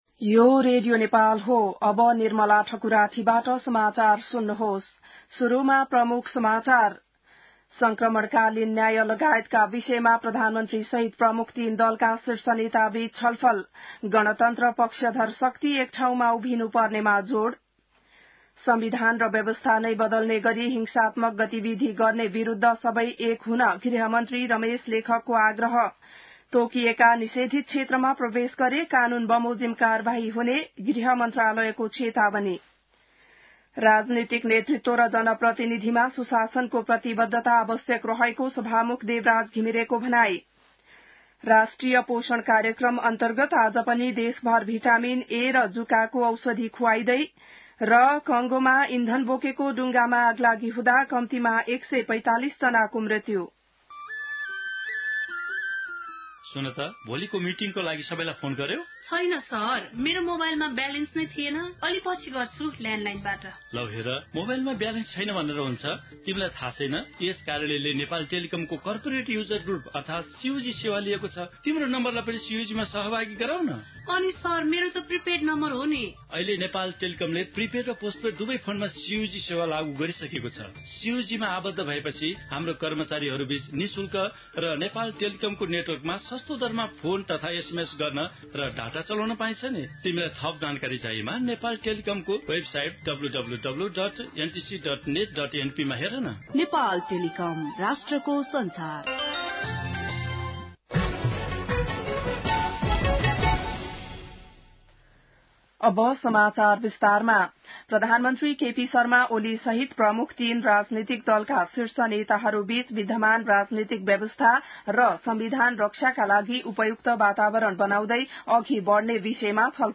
बिहान ७ बजेको नेपाली समाचार : ७ वैशाख , २०८२